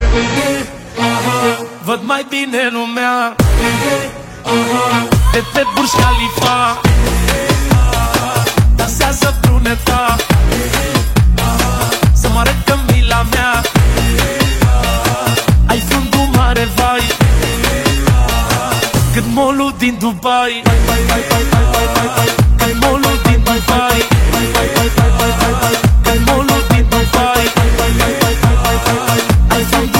Powerful Arabic-style bass ringtone for mobile.